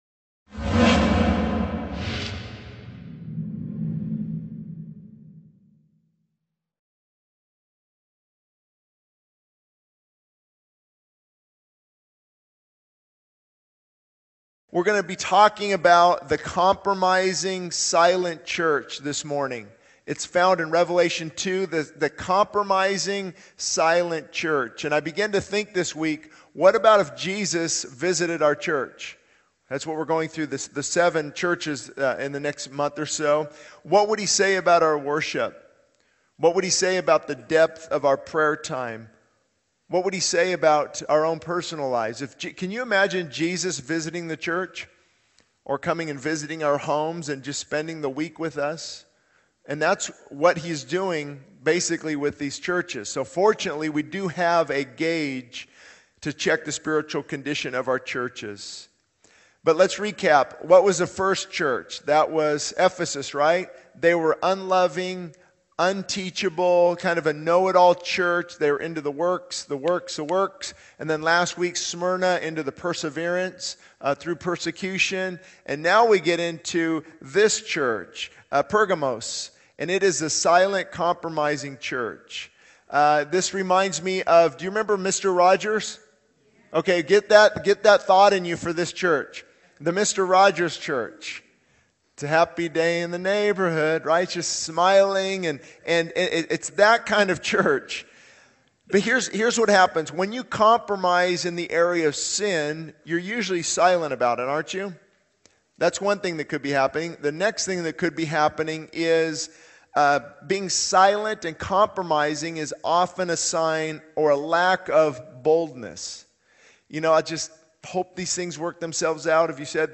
This sermon delves into the concept of the compromising silent church as depicted in Revelation 2. It challenges listeners to consider what Jesus would say about their worship, prayer life, and personal conduct if He visited their church. The sermon emphasizes the need to address compromise, remove destructive influences, and pursue purity to experience God's power.